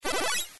get_bullet.wav